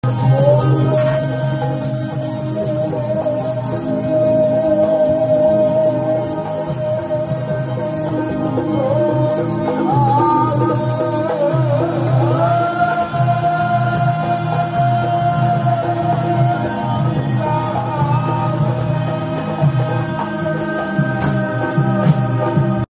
Diwan MP3 Audio by Bhai Ranjit Singh Ji khalsa Dhadrian wale
Monthly Congregation 2 Aug dhadrianwale bhairanjitsinghdhadrianwale